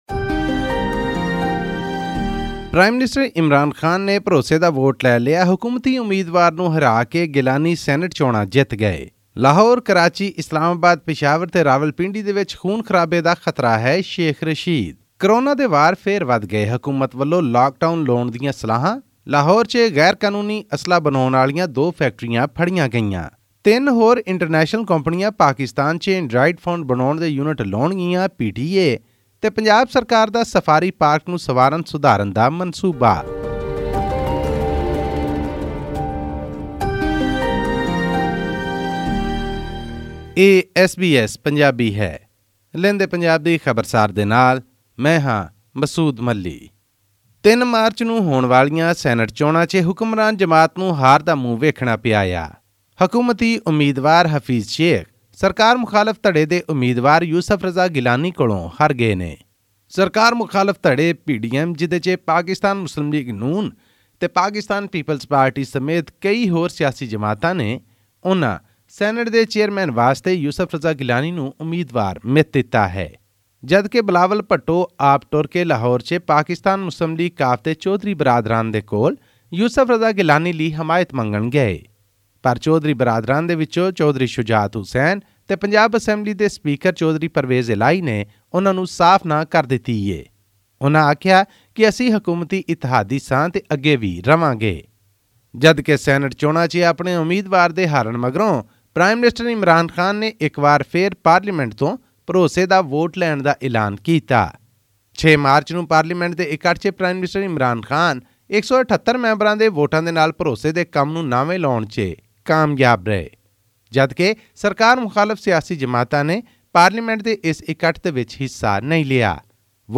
Pakistan Prime Minister Imran Khan secured 178 votes, against the 172 required to win confidence that he sought from the lower house of parliament earlier this week. This and more in our weekly news segment from Pakistan.